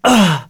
Arch-Vox_Damage1.wav